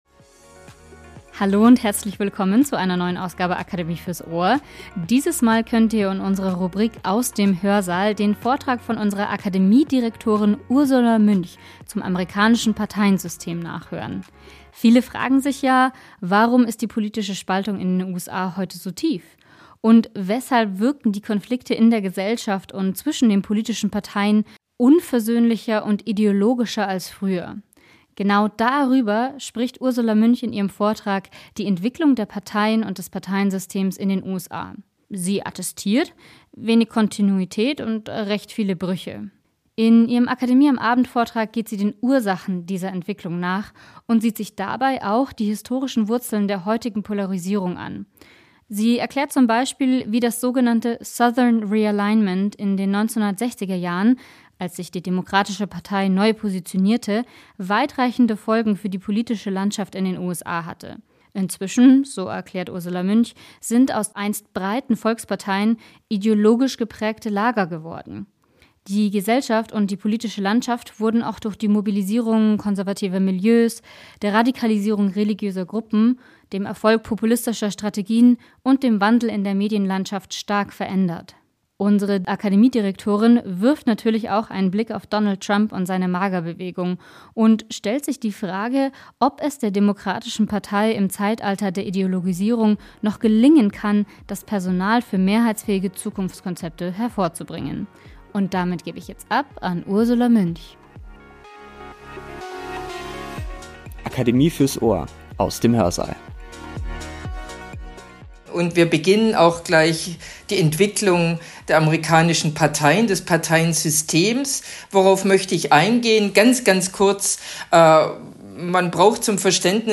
Beschreibung vor 3 Monaten In unserer Podcast-Rubrik "Aus dem Hörsaal" gibt es spannende Vorträge des Akademie-Kollegiums zum Nachhören.